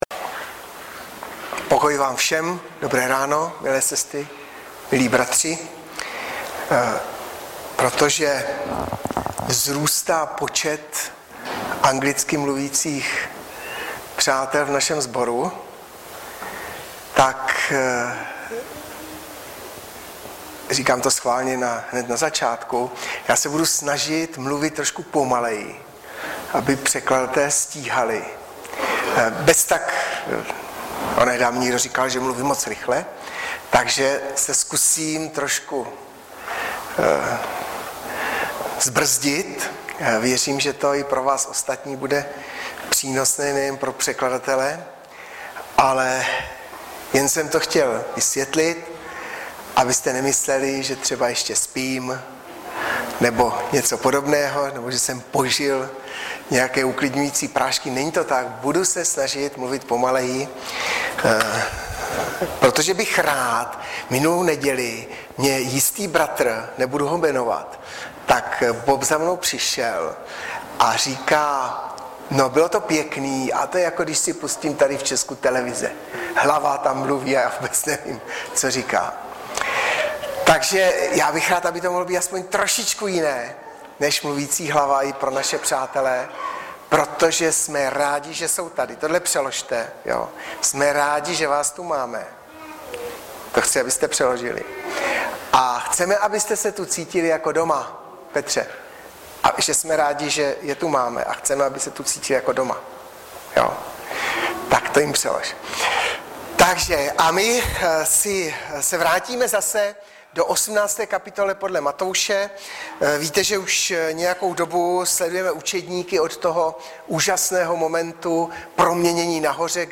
Webové stránky Sboru Bratrské jednoty v Litoměřicích.
Kázání